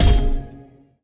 poly_explosion_holy03.wav